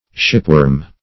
Shipworm \Ship"worm`\, n. (Zool.)